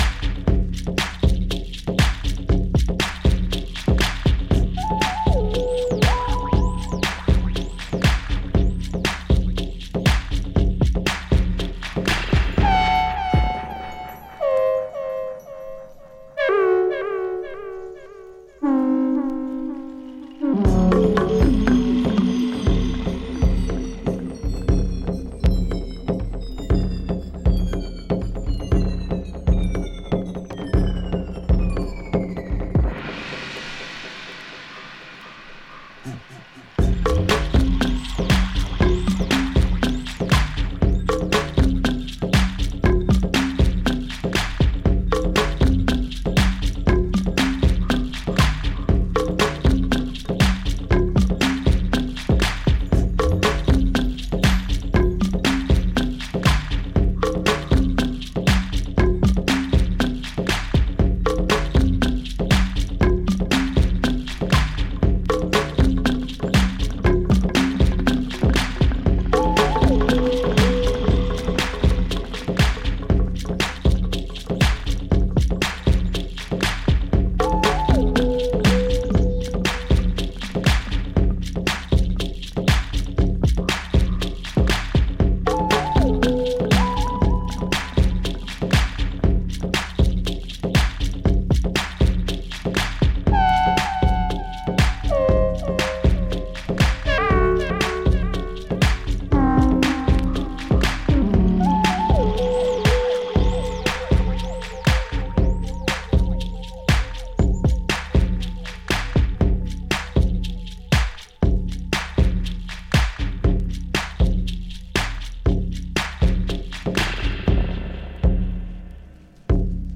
ユーモラス、かつ黒い狂気が充満している傑作です。